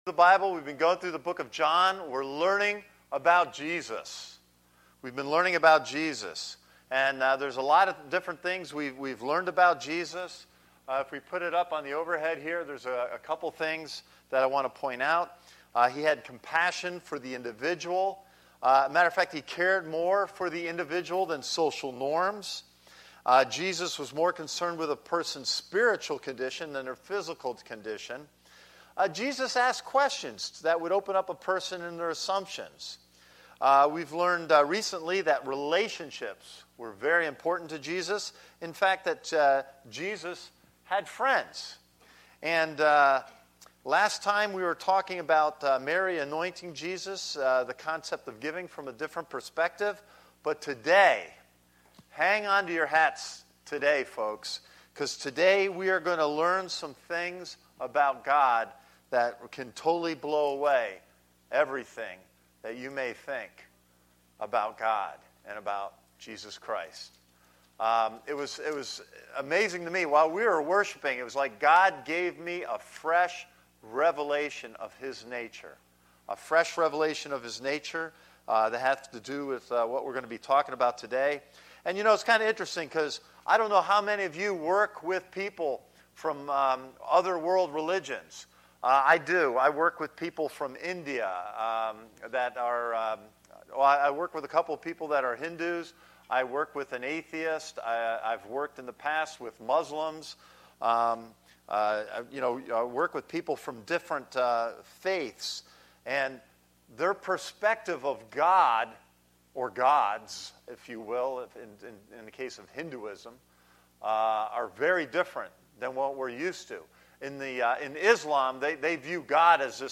A message from the series "Sunday Service."
This is the main Sunday Service for Christ Connection Church